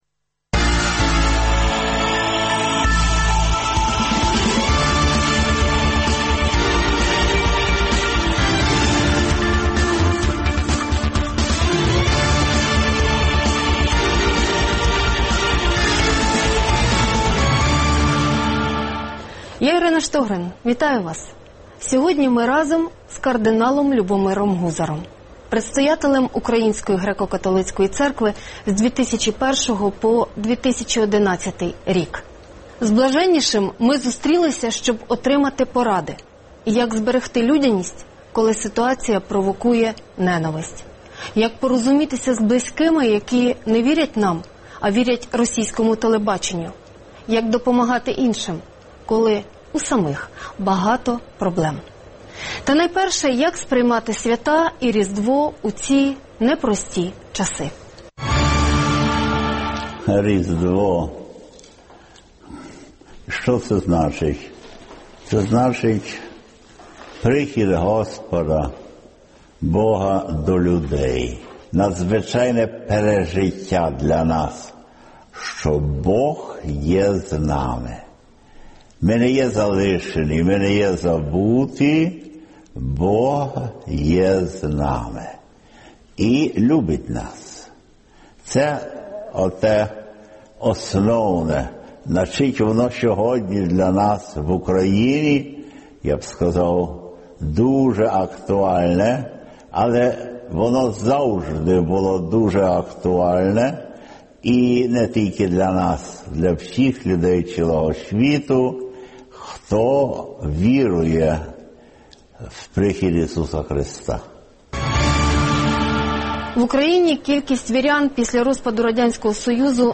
Гість: Кардинал Любомир Гузар, предстоятель Української Греко-Католицької церкви у 2001-2011 р.р